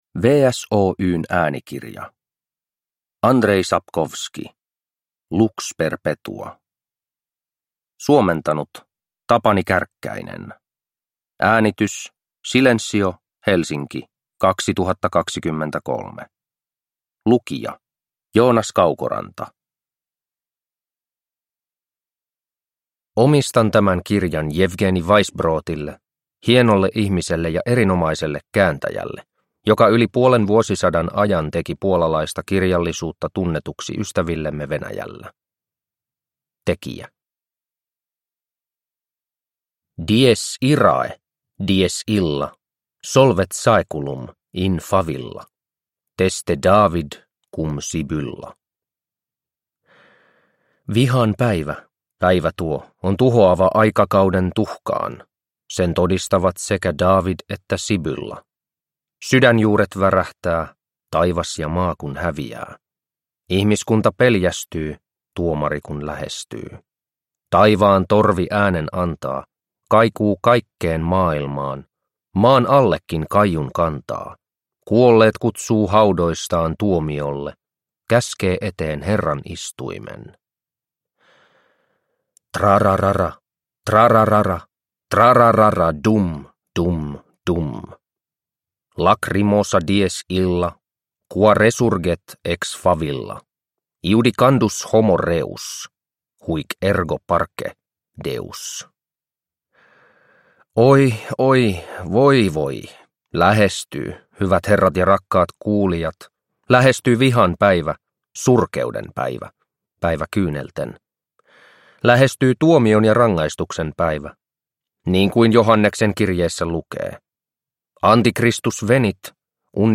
Lux perpetua – Ljudbok – Laddas ner